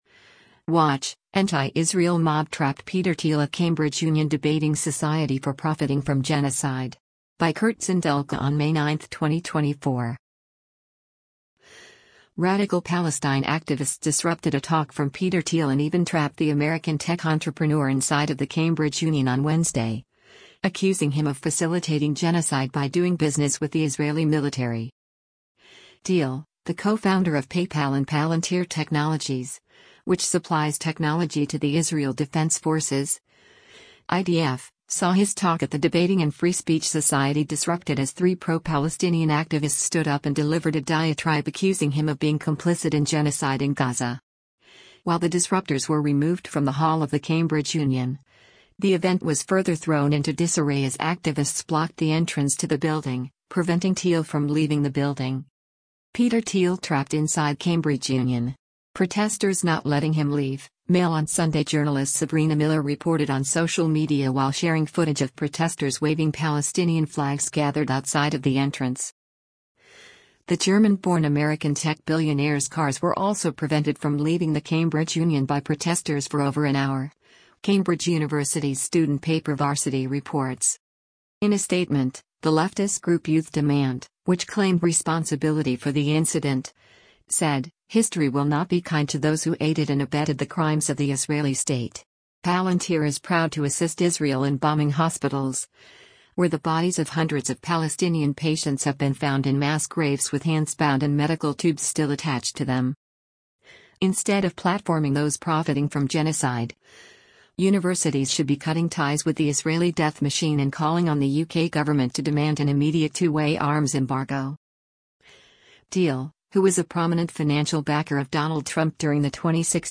Thiel, the co-founder of PayPal and Palantir Technologies, which supplies technology to the Israel Defense Forces (IDF), saw his talk at the debating and free speech society disrupted as three pro-Palestinian activists stood up and delivered a diatribe accusing him of being complicit in genocide in Gaza.